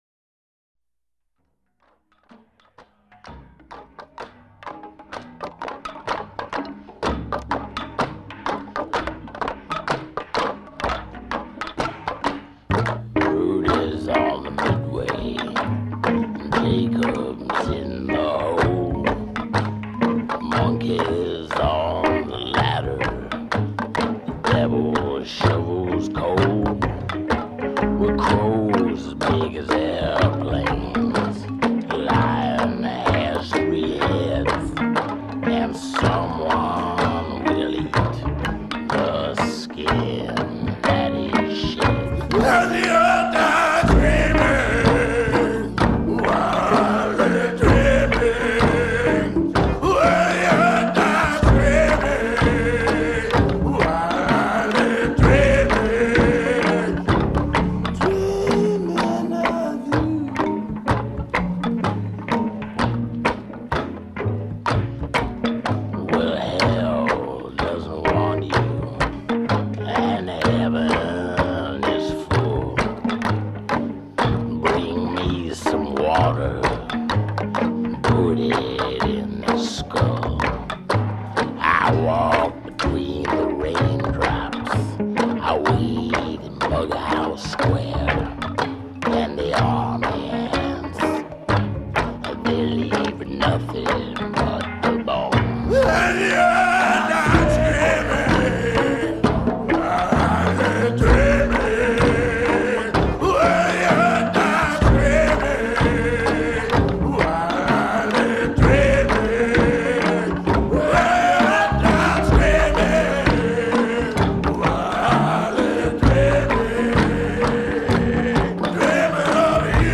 نسخه کلاسیک و کمیاب با صدای خشن و بی‌نظیر
Experimental Rock, Blues, Avant-Garde